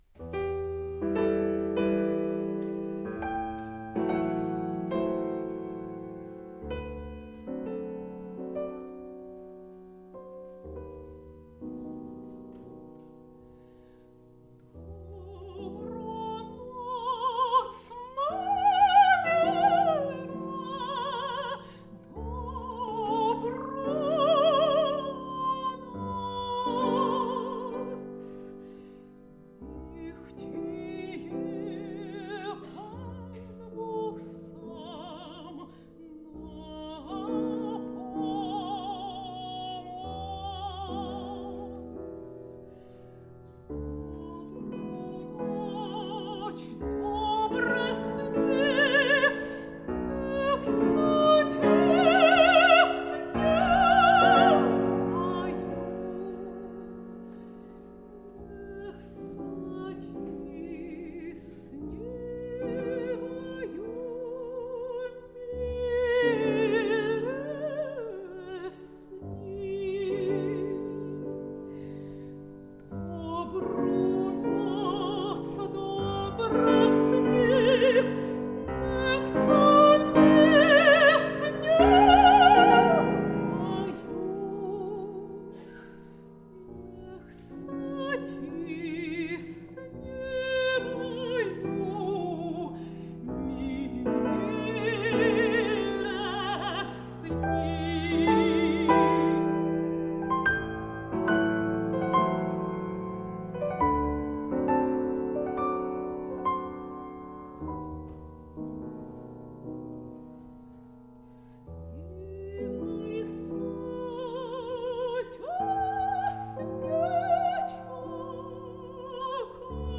Voice  (View more Intermediate Voice Music)
Classical (View more Classical Voice Music)